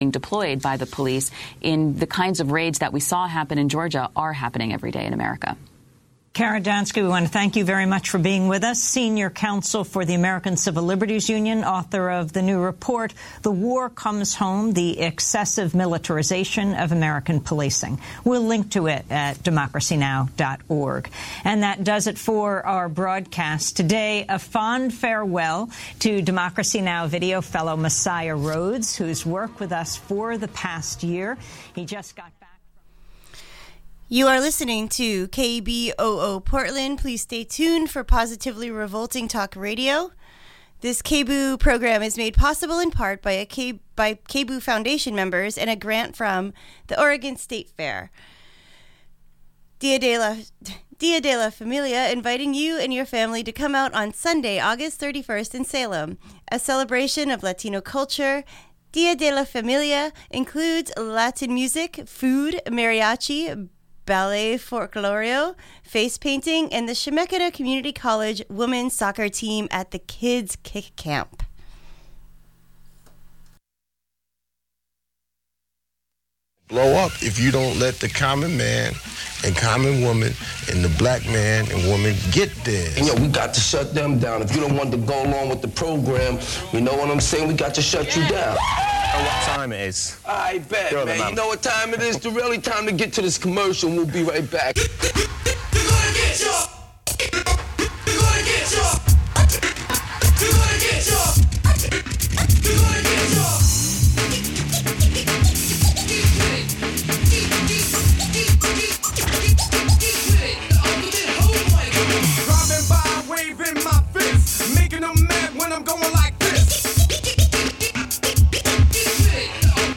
Listeners (particularly KBOO members) are encouraged to phone in with thier questions for the ca...